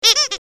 clock04.mp3